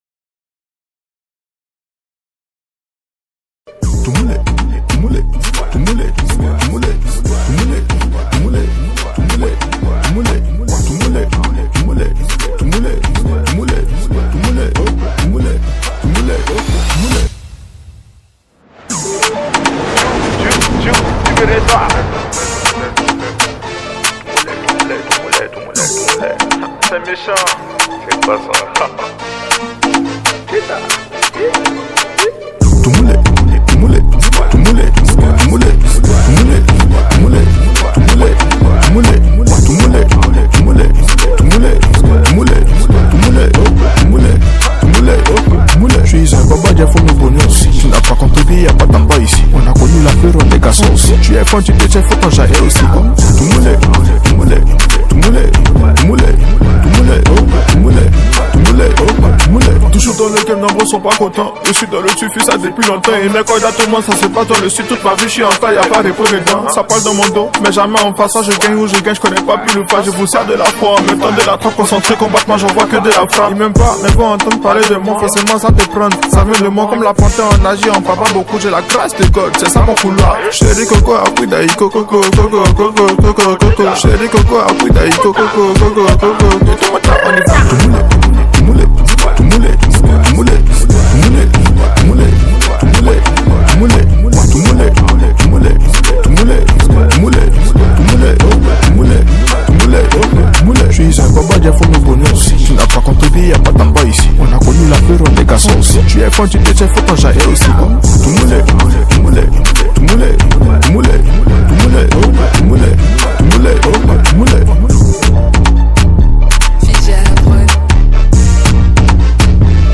Enjoy this banger produced by himself.